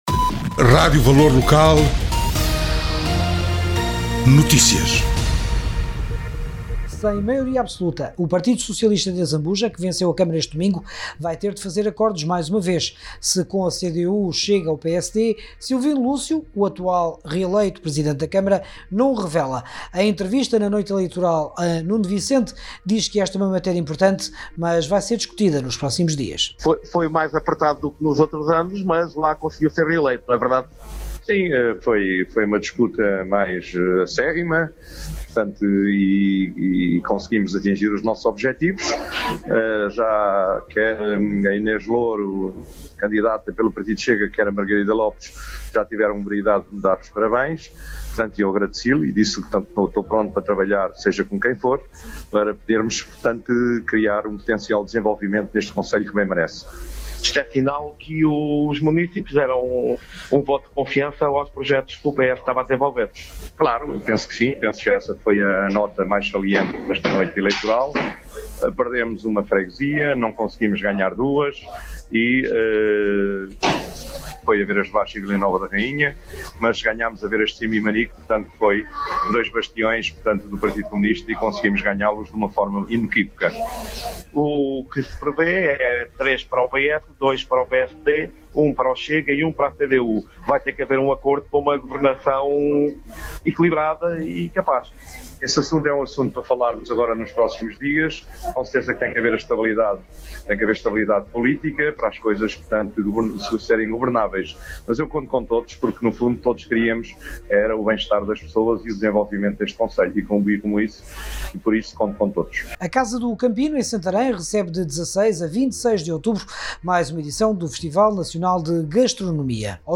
A Rádio Valor Local registou em primeira mão as declarações do grande vencedor da noite eleitoral em Azambuja.